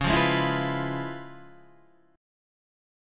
Db7#9 chord